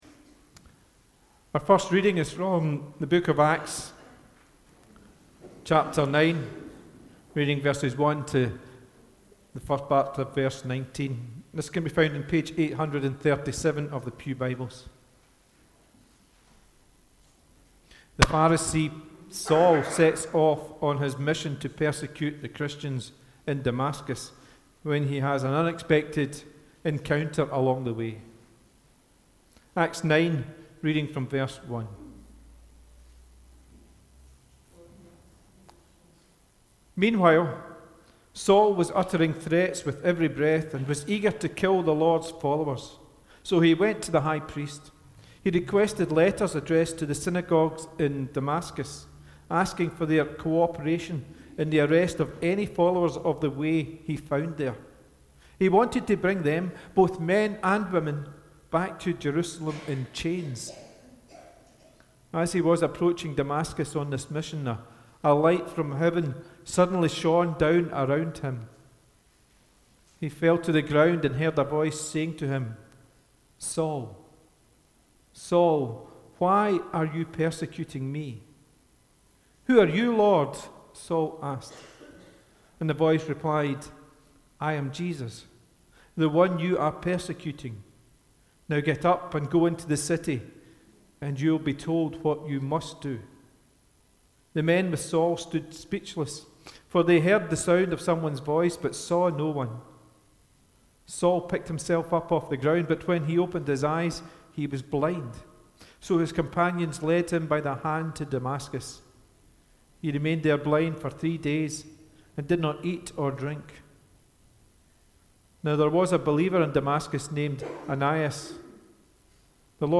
The Reading prior to the Sermon is Acts 9: 1-30 (NLT)